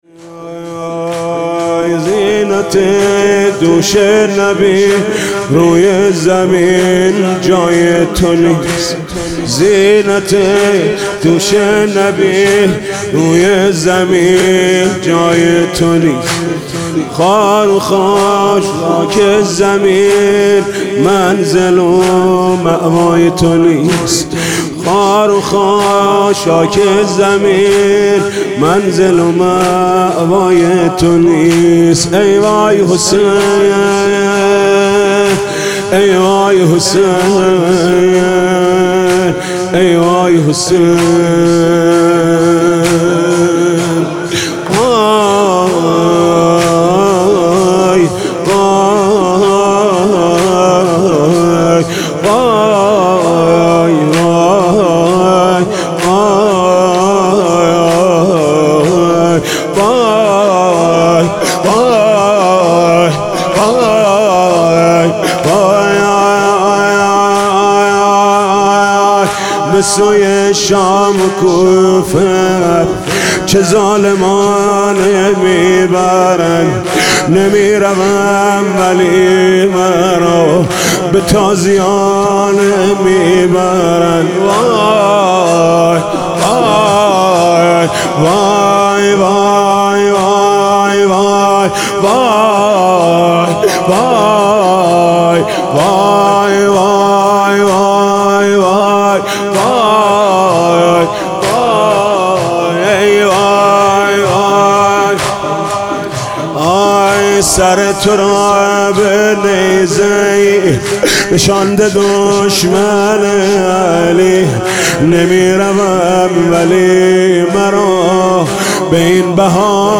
شور: زینت دوش نبی روی زمین جای تو نیست